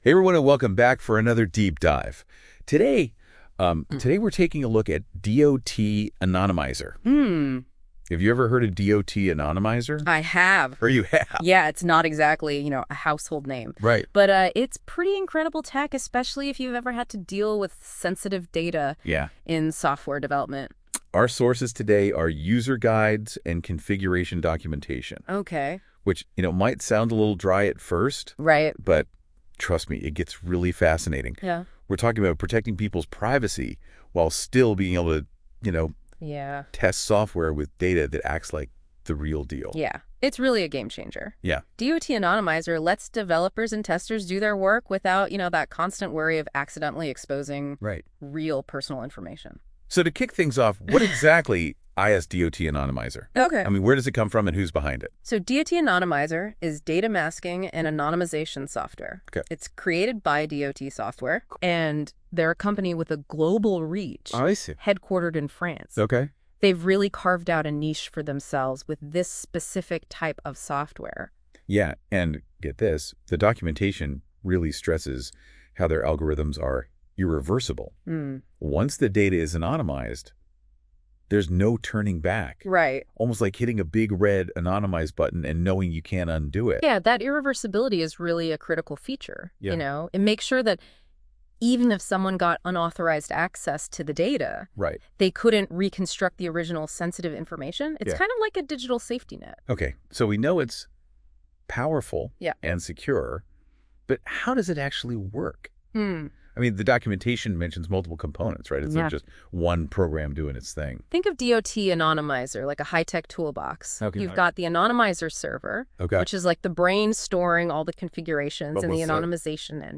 In this podcast, entirely hosted by AI, our two generated speakers take you on a complete tour of DOT Anonymizer, a data anonymization tool.